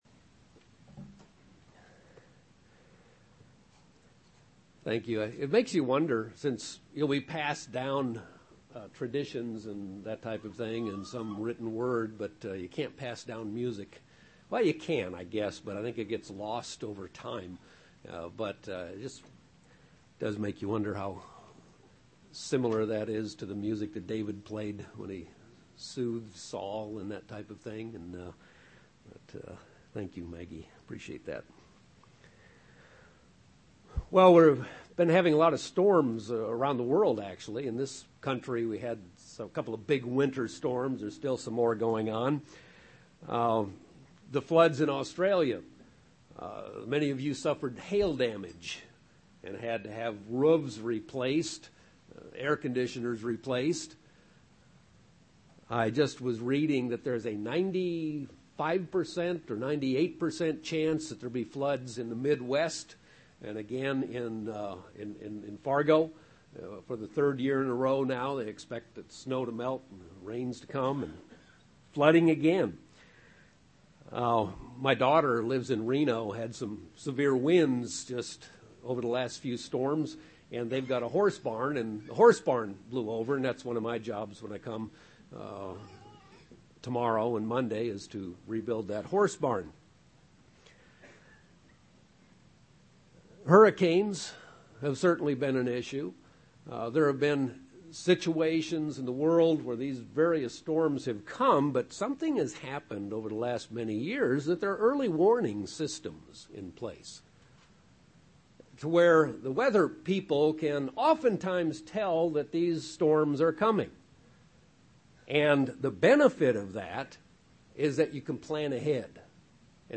UCG Sermon Studying the bible?